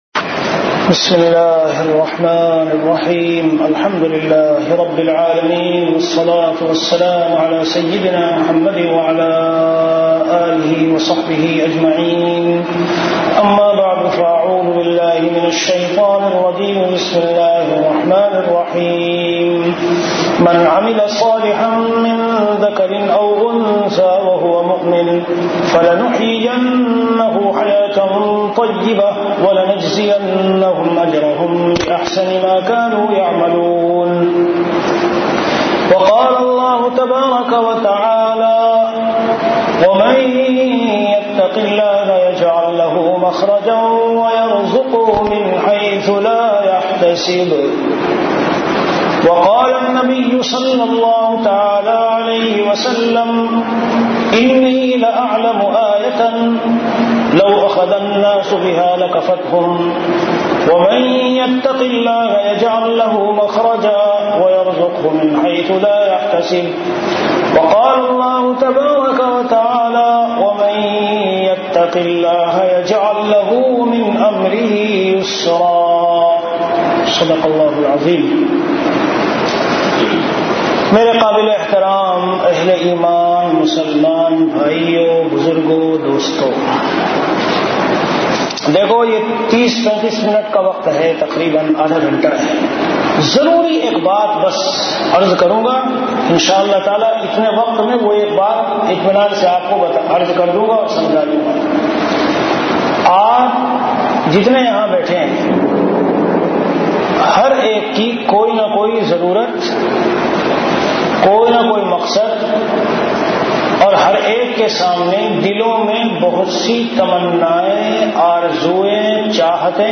Delivered at Qatar.
Before Juma Prayer